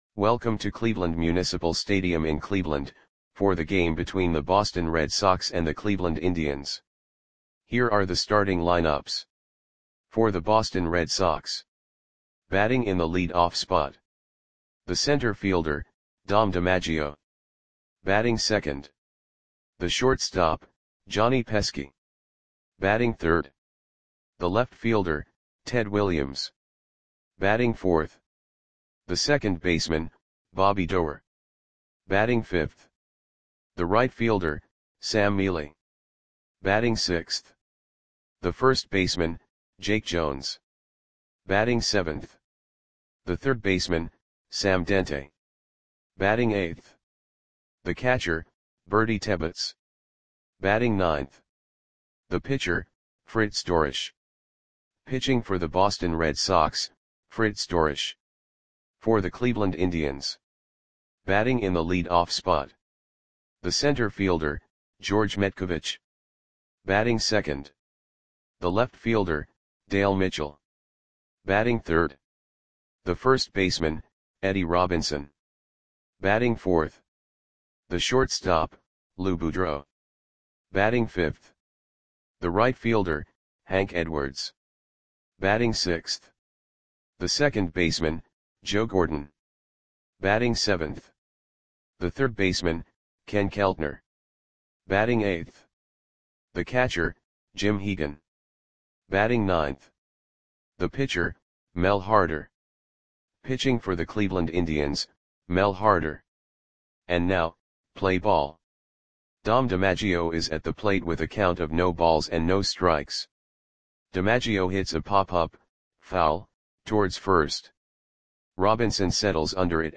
Audio Play-by-Play for Cleveland Indians on July 13, 1947
Click the button below to listen to the audio play-by-play.